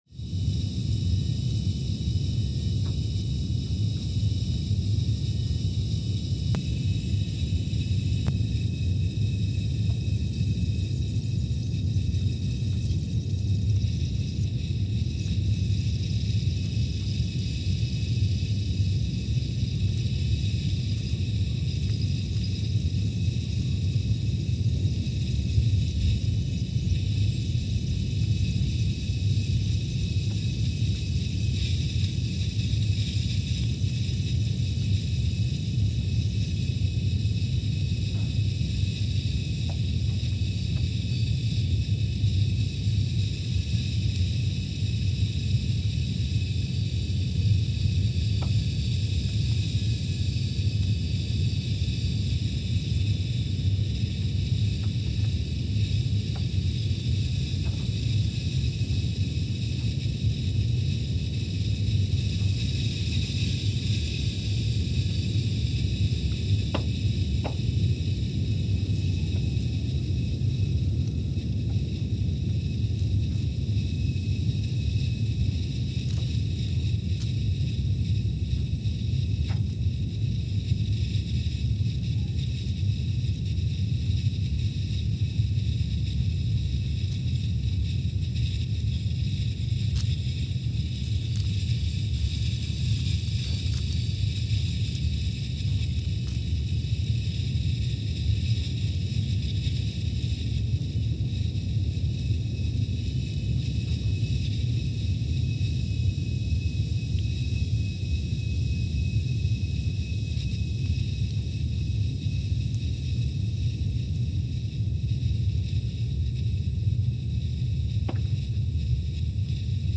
Scott Base, Antarctica (seismic) archived on May 14, 2022
Sensor : CMG3-T
Speedup : ×500 (transposed up about 9 octaves)
Gain correction : 25dB
SoX post-processing : highpass -2 90 highpass -2 90